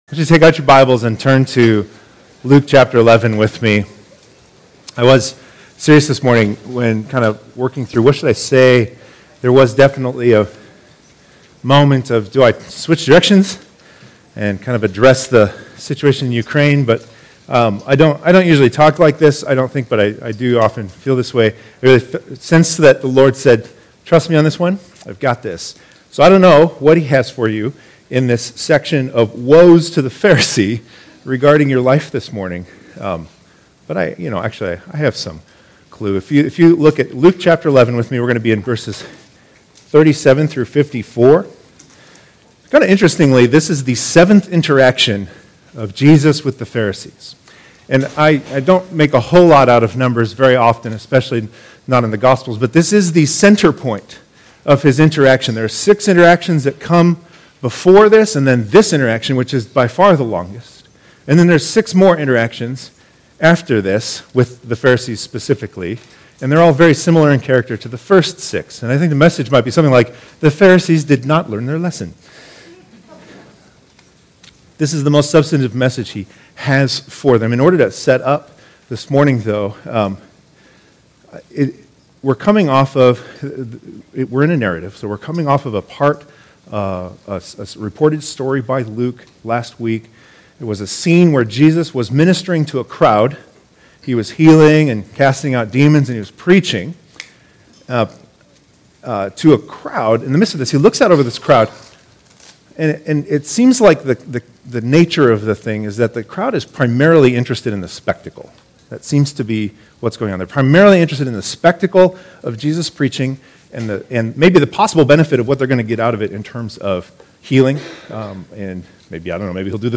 In this week’s sermon, we look at a scene in which a question about washing up before a meal provokes a seemingly very harsh response from Jesus.